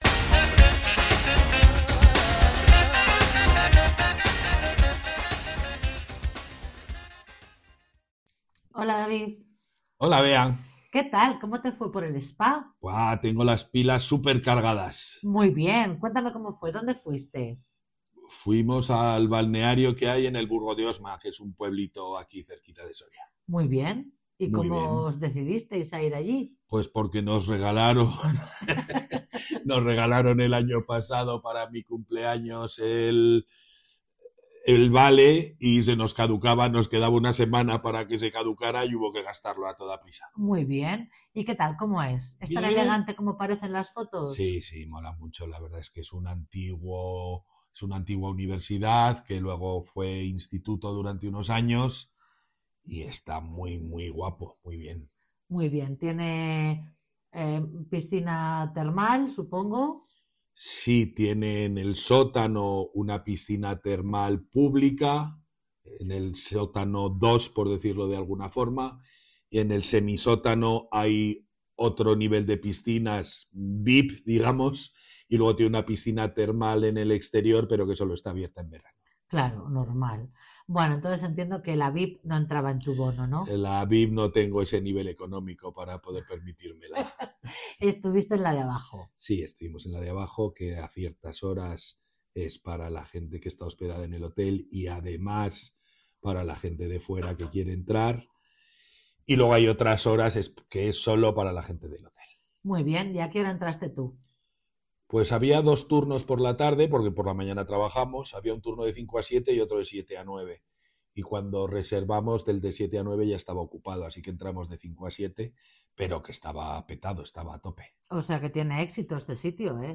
Real conversations recorded for all learners of Spanish with worksheets and transcripts